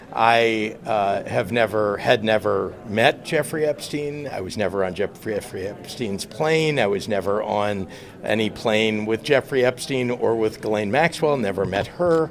Gov. Pritzker spoke in Chicago on Tuesday and said the former president was mistaken.